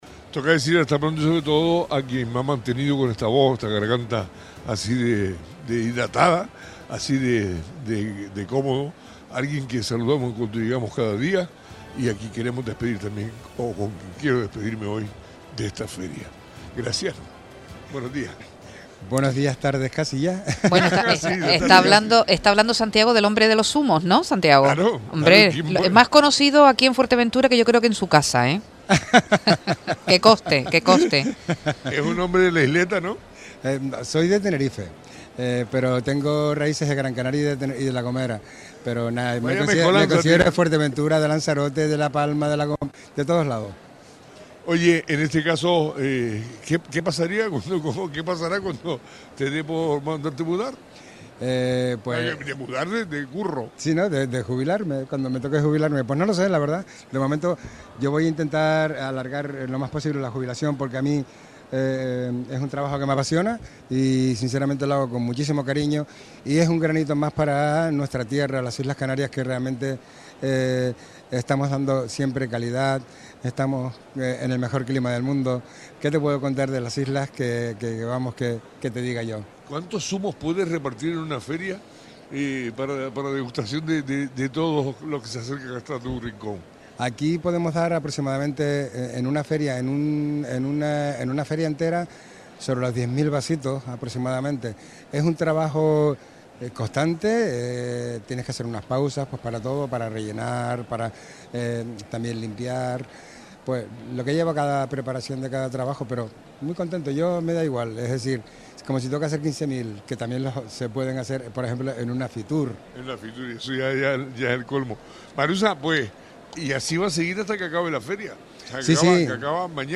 Radio Sintonía cierra su programación especial desde la ITB 2025